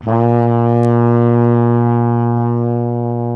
TENORHRN A#0.wav